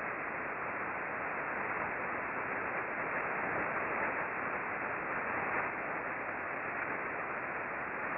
We observed mostly S-bursts throughout the pass. There also was considerable interference from sweepers and other repetitive signals.